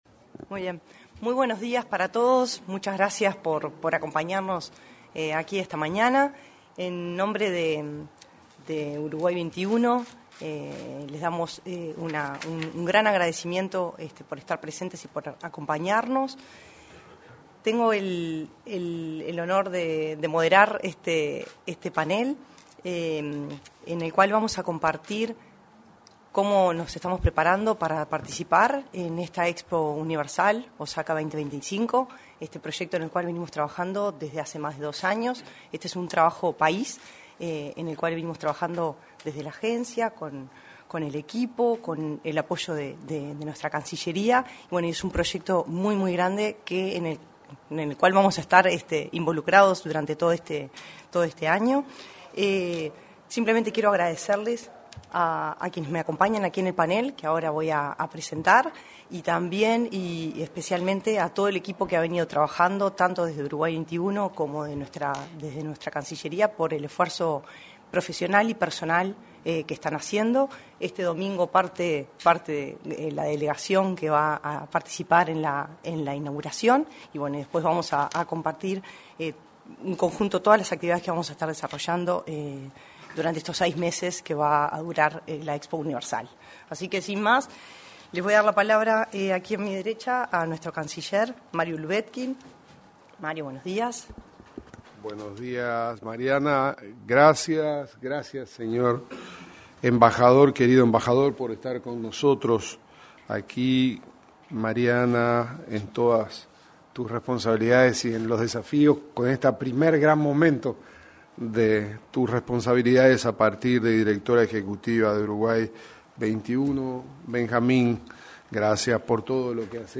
En la oportunidad, se expresaron la directora ejecutiva de Uruguay XXI, Mariana Ferreira; el ministro de Relaciones Exteriores, Mario Lubetkin; el embajador de Japón en Uruguay, Kenichi Okada, y el comisario de Uruguay en la referida muestra, Benjamín Liberoff.